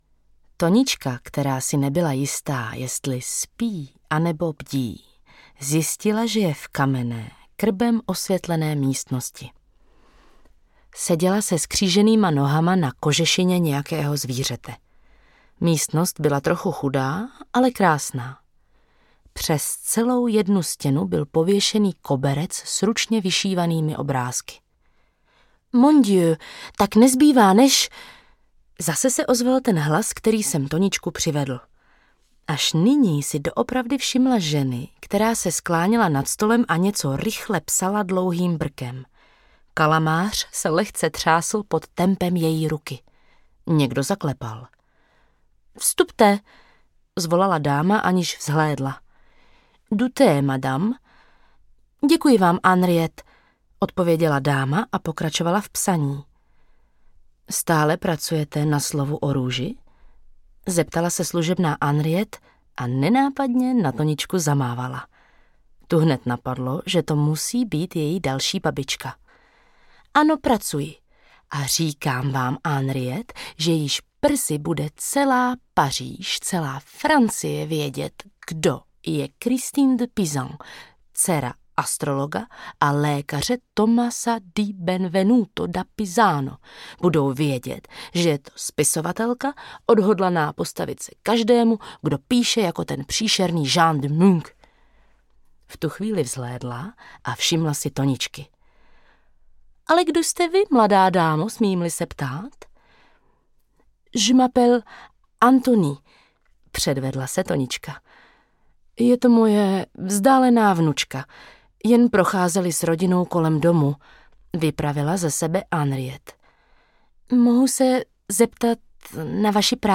Atlas babiček audiokniha
Ukázka z knihy
Vyrobilo studio Soundguru.